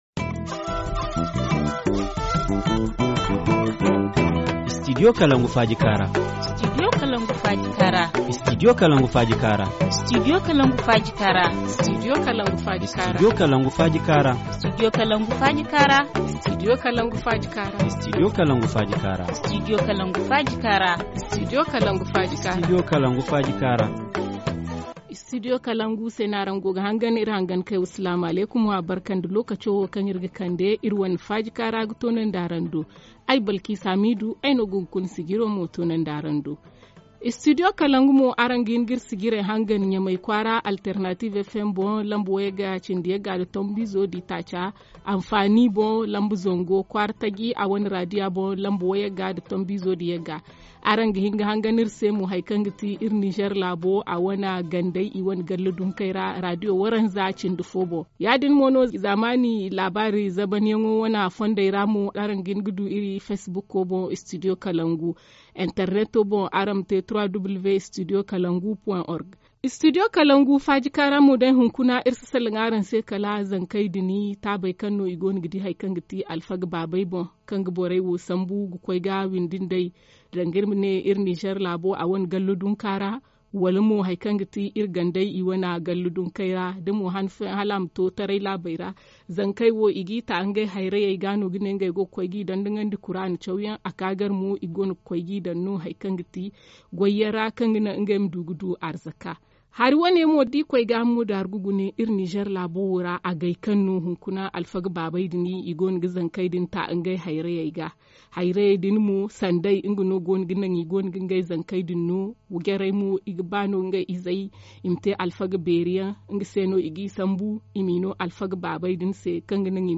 Sociologue